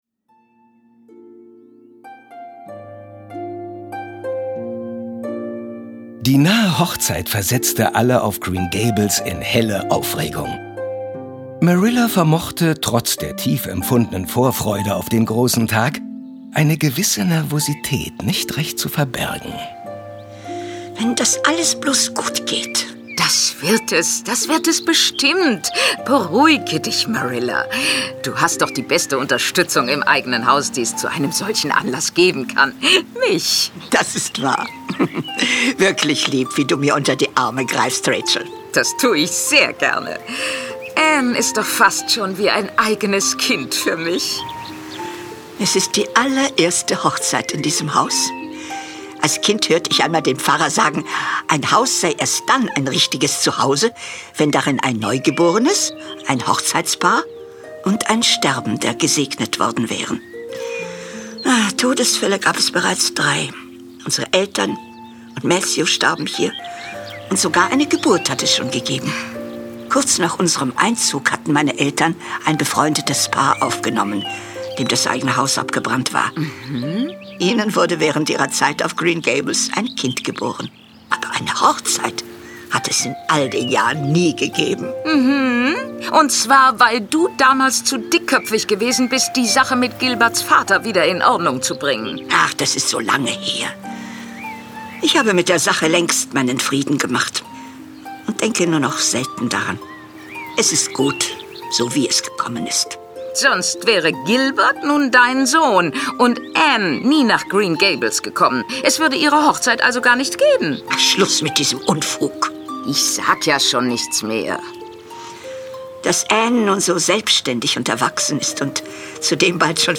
Ein neues Zuhause. Hörspiel.